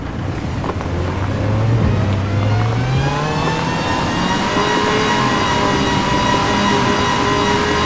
Index of /server/sound/vehicles/lwcars/caterham_r500
rev.wav